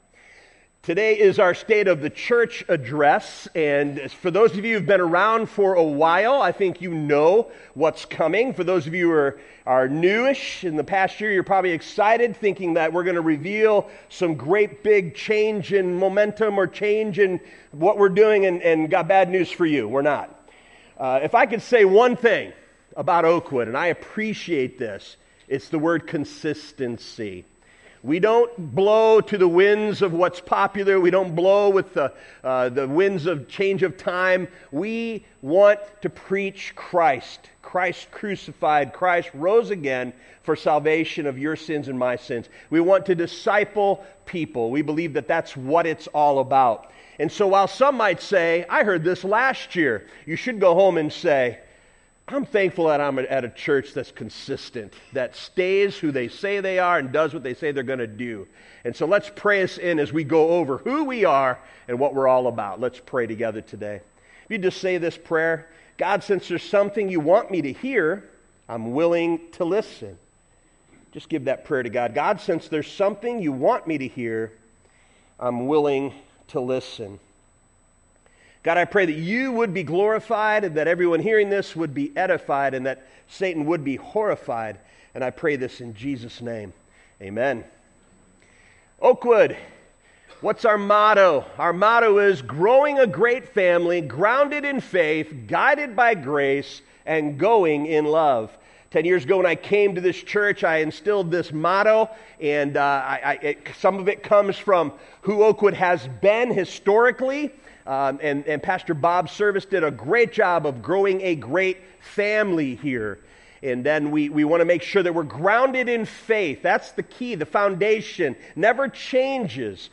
Oakwood Community Church Message Podcast | Oakwood Community Church